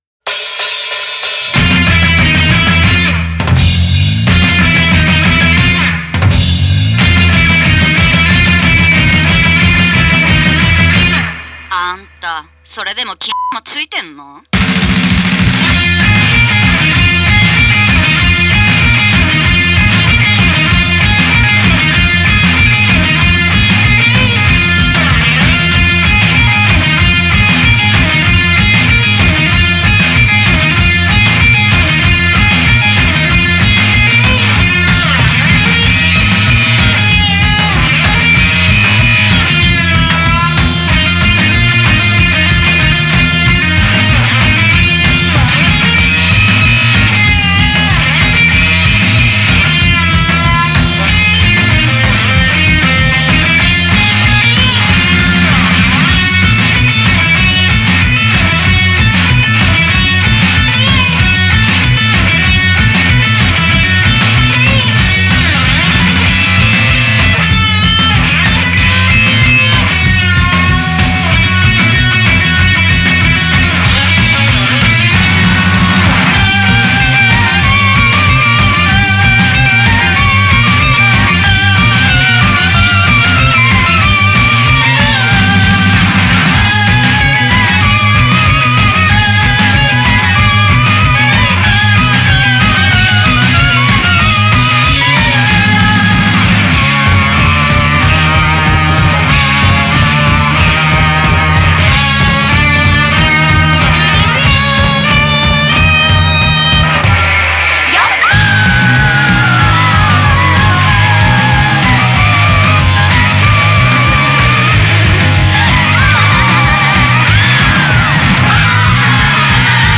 Genre: Psychedelic Rock, Garage